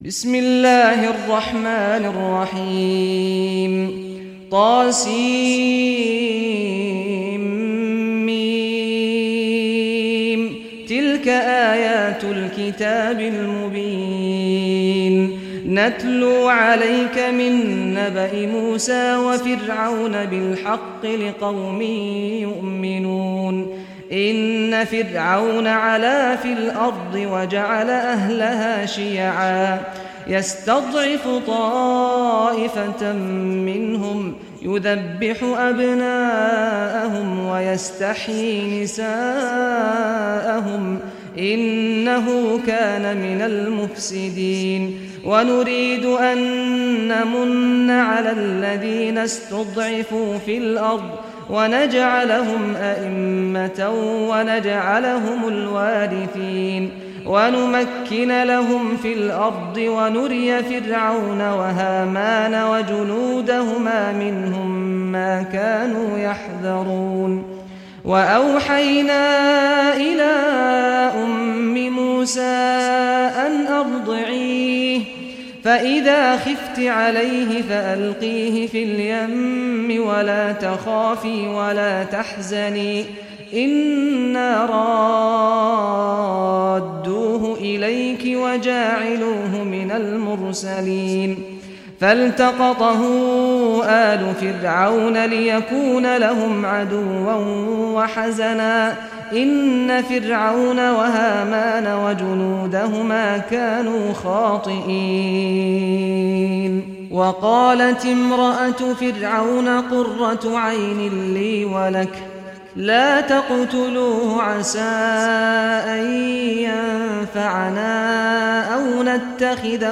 Surah Al-Qasas Recitation by Sheikh Saad Ghamdi
Surah Al-Qasas, listen or play online mp3 tilawat / recitation in Arabic in the voice of Sheikh Saad al Ghamdi.